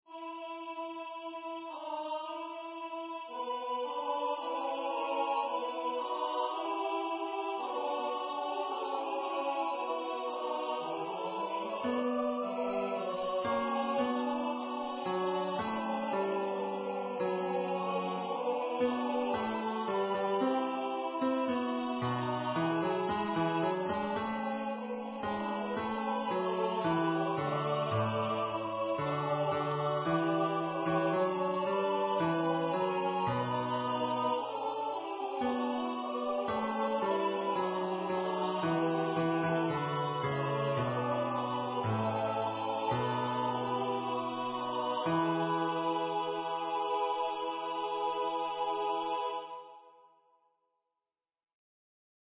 Number of voices: 4vv   Voicing: SATB
Genre: SacredMass
Instruments: A cappella